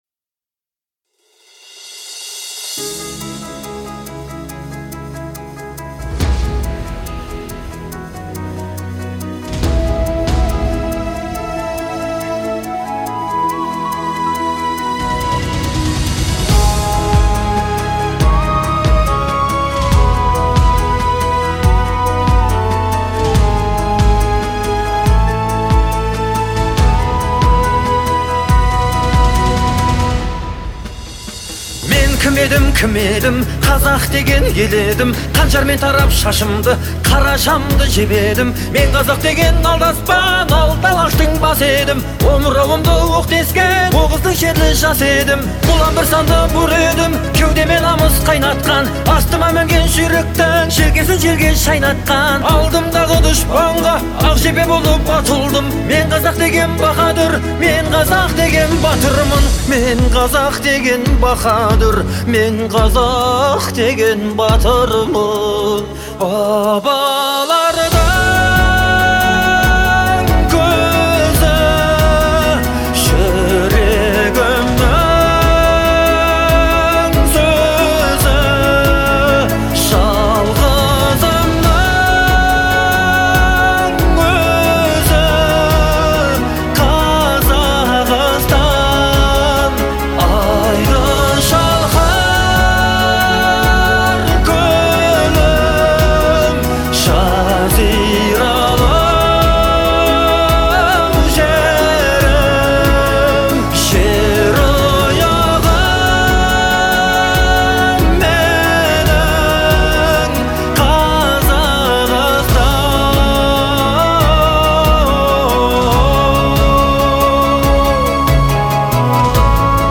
отличается эмоциональной насыщенностью и мощным вокалом